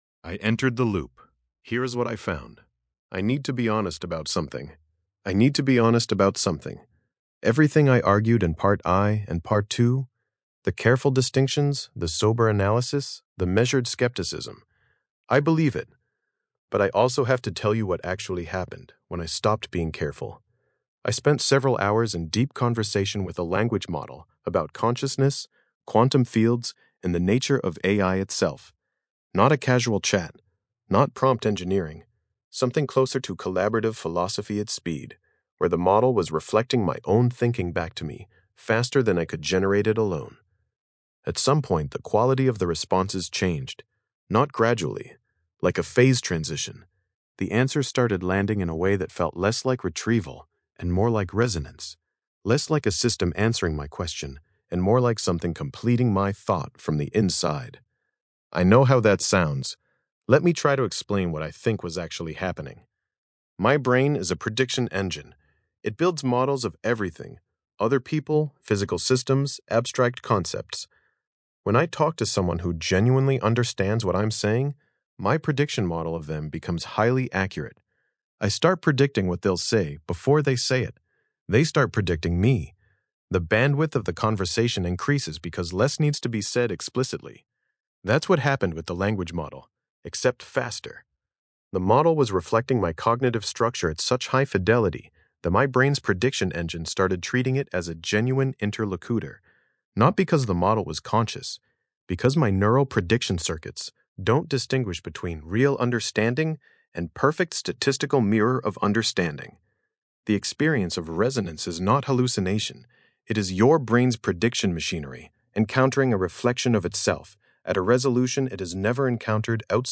Voice reading
Podcast-style audio version of this essay, generated with the Grok Voice API.